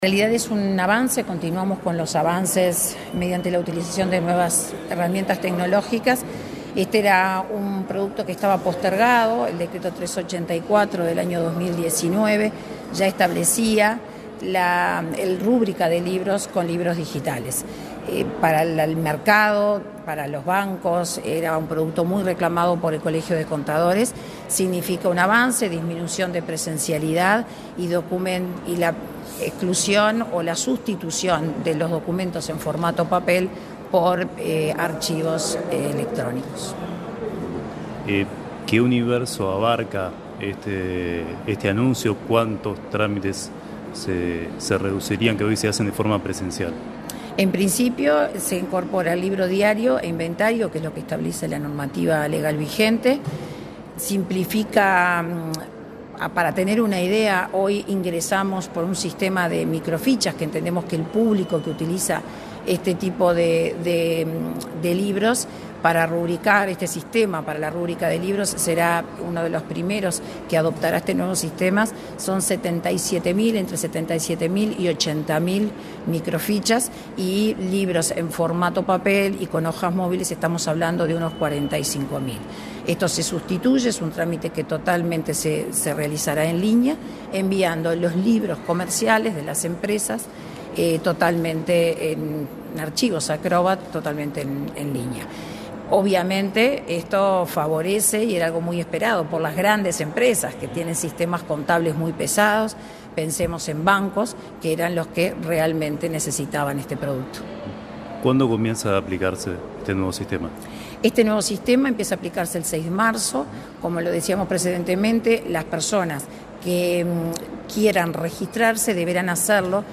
Entrevista a la directora general de Registros, Daniella Pena